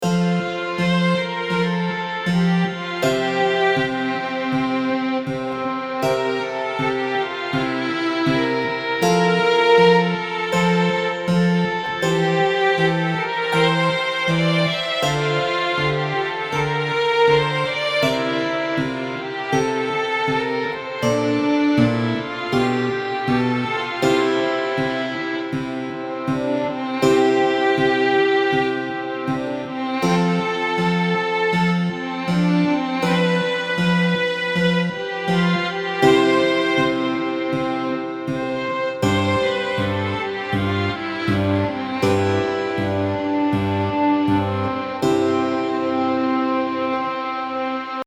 Partitura do segundo movemento (Largo, fragmento) do concerto "O inverno" ("As catro estacións", A. Vivaldi)
Entonar as notas da voz do violín (voz superior) mentres escoitamos o audio "O inverno Vivaldi - máster"